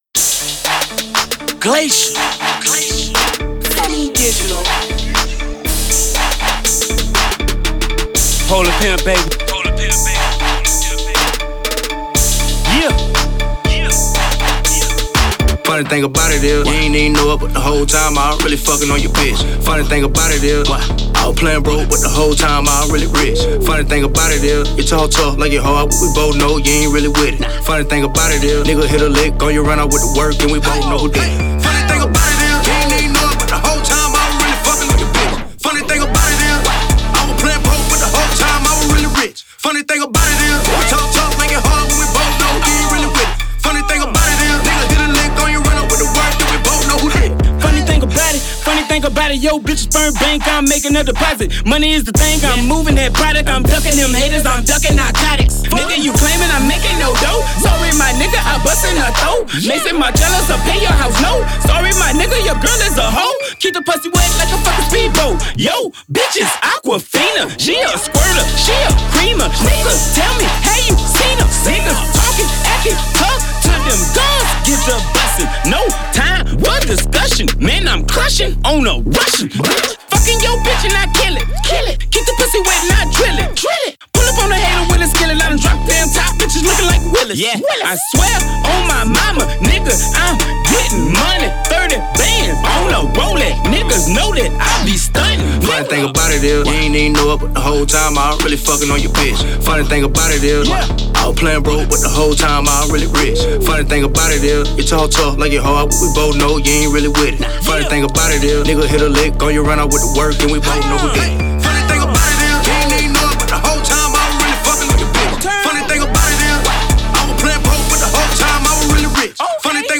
THIS RECORD IS STRONG, POWERFUL, AND A DEFINITE BANGER.